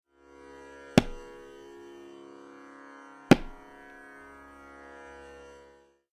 (Bols below: recorded on my tabla)
• Ti/Te: Some combination of the middle/ring/pinky fingers (depending on context) strike the centre of the syahi, producing a sharp, non-resonant sound.
TALAS-Bol-Syllable-TiTe.mp3